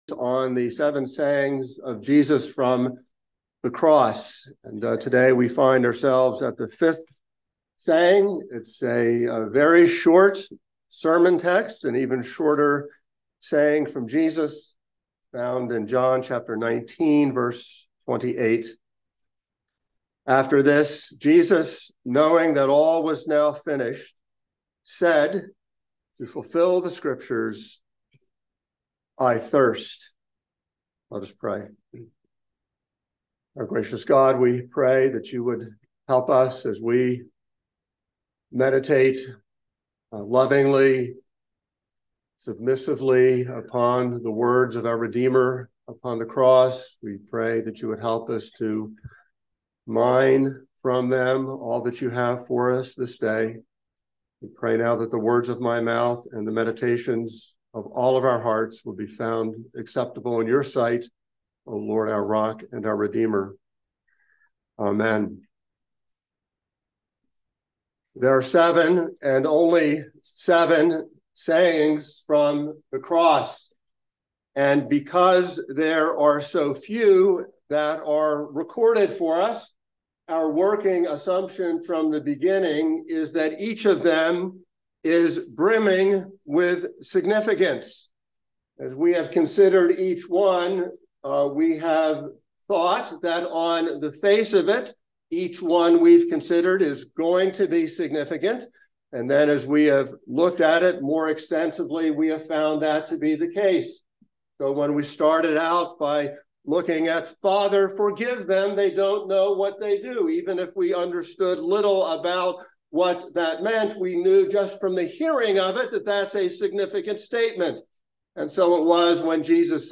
by Trinity Presbyterian Church | Jan 5, 2024 | Sermon